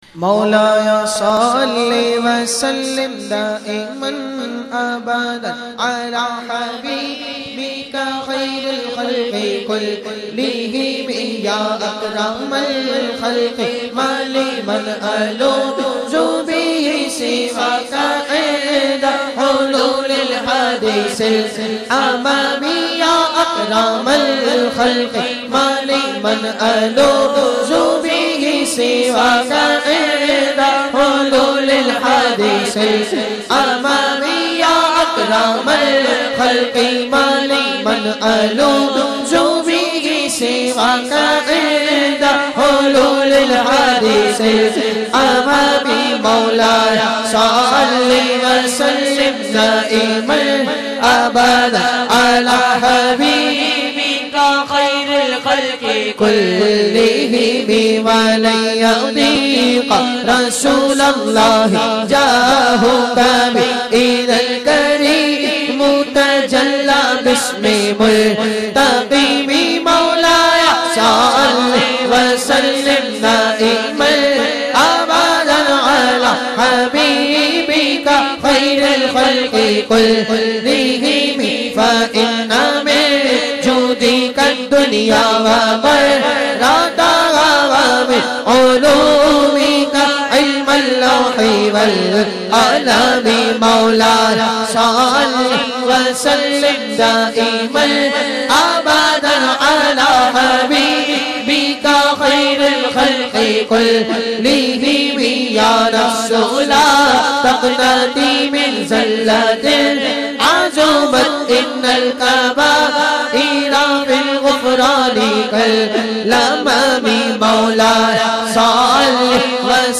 recited by famous Naat Khawan of Pakistan